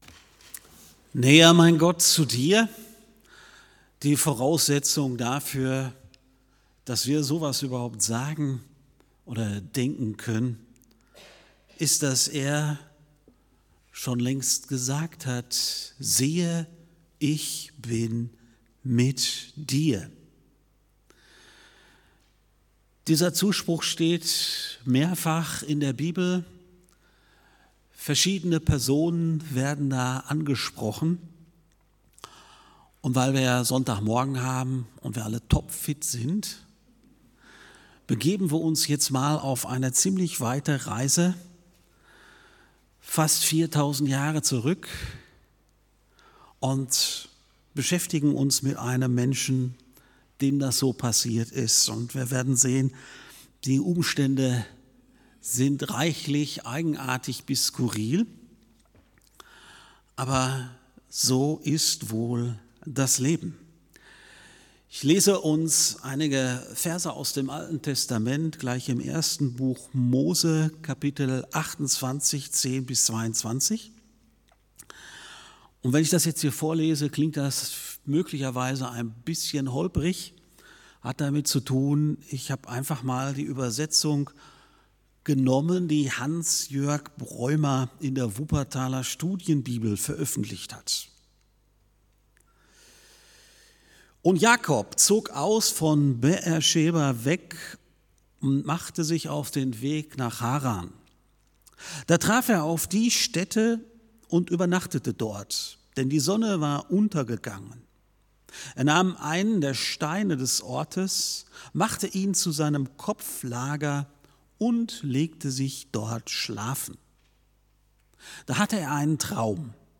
~ FeG Aschaffenburg - Predigt Podcast